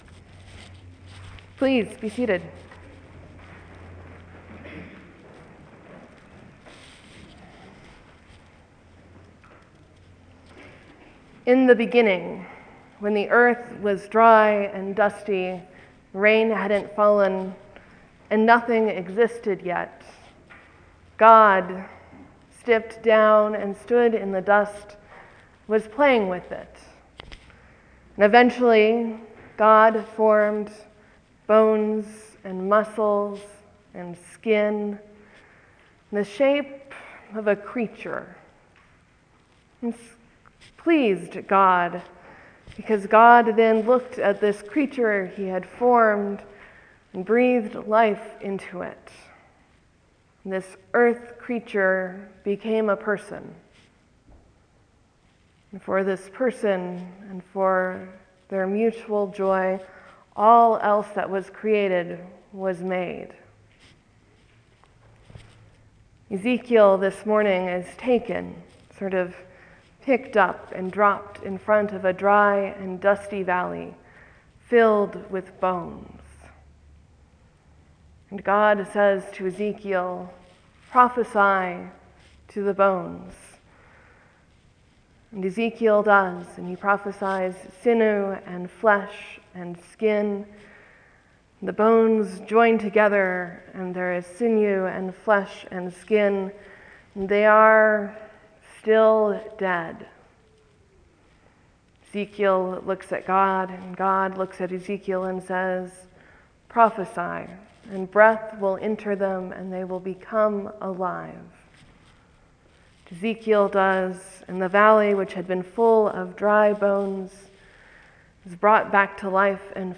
An Anglican/Episcopal priest, bibliophile, dog owner, and Montanan